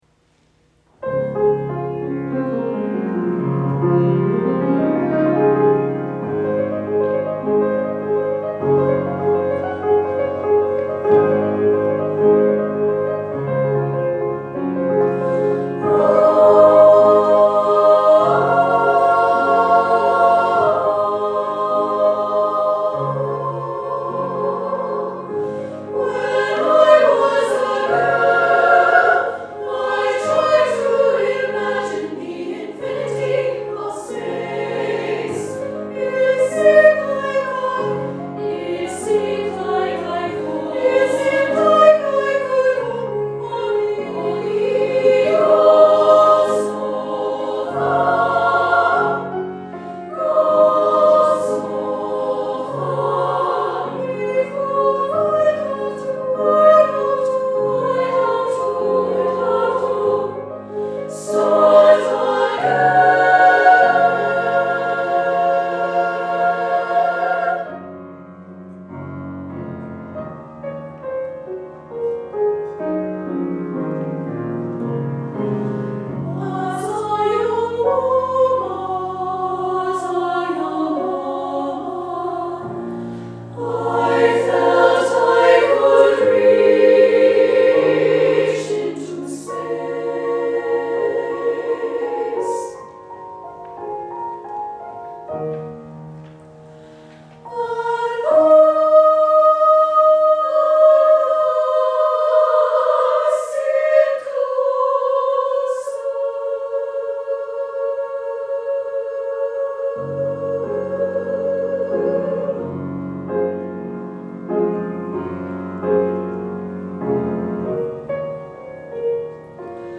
for SSAA, S solo, piano
Set over a shimmering piano accompaniment